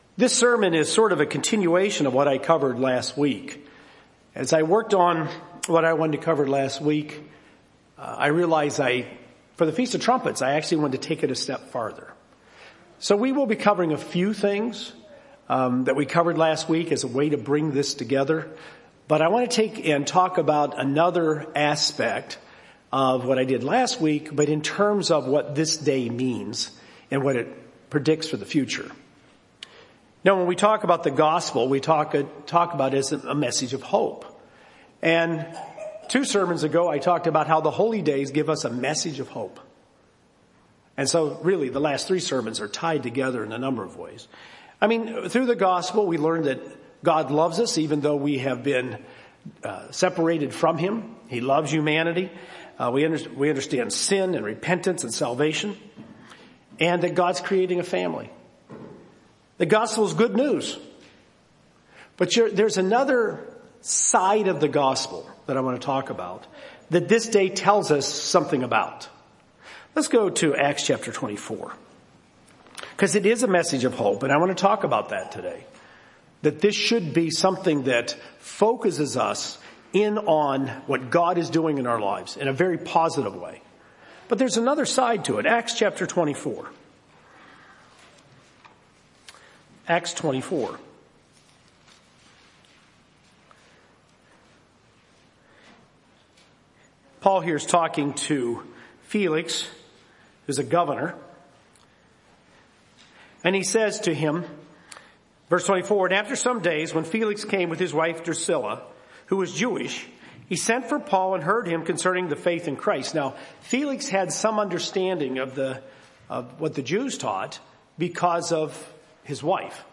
There’s an easily overlooked aspect of the Gospel message, but one that’s critical to understand, and it ties in with the prophetic events pictured on the Feast of Trumpets. Given on the Feast of Trumpets 2021.